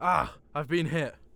Voice Lines / Barklines Combat VA
Marcel hit.wav